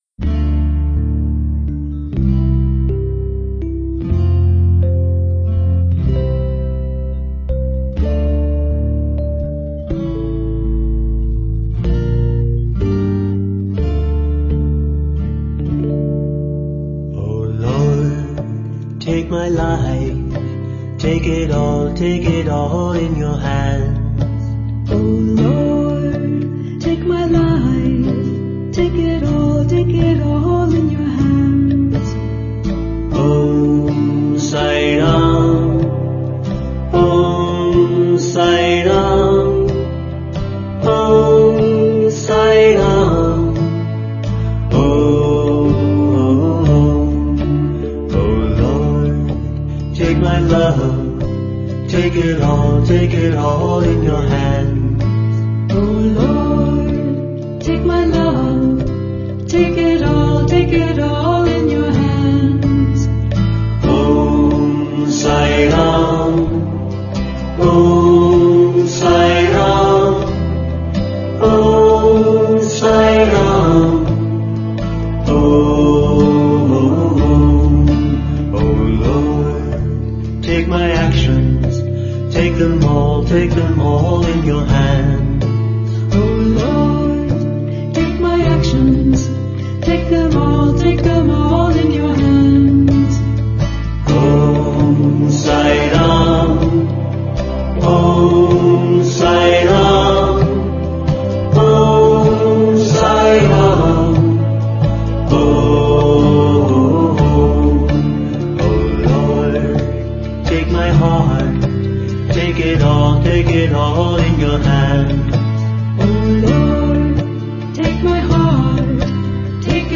Harmonic Minor 8 Beat  Men - 5 Pancham  Women - 2 Pancham
Harmonic Minor
8 Beat / Keherwa / Adi
5 Pancham / G
2 Pancham / D